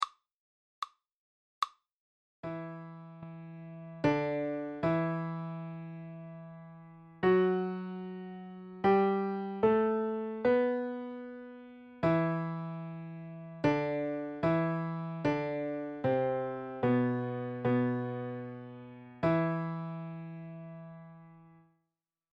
e minor Hear the answer